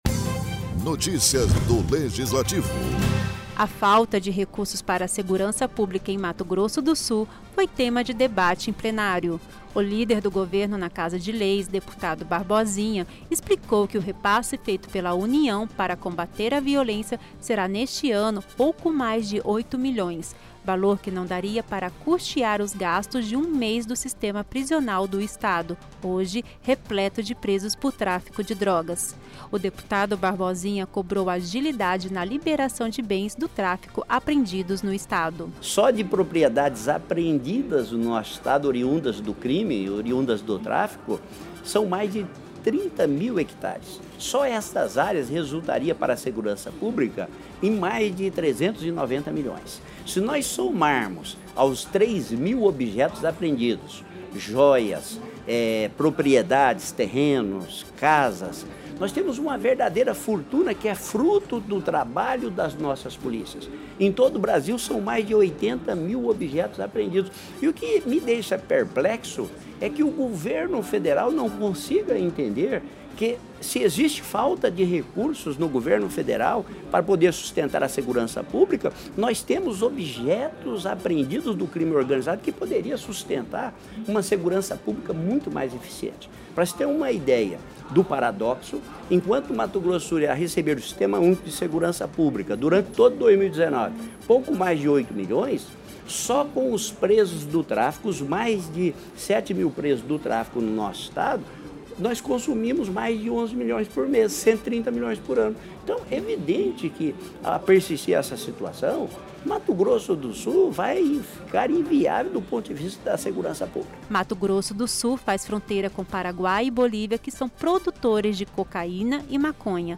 O deputado Barbosinha (DEM), subiu à tribuna nesta quarta feira (27) para expressar sua preocupação com a segurança pública do Estado, em relação às áreas de fronteira. Ele solicita mais recursos para a segurança publica em Mato Grosso do Sul .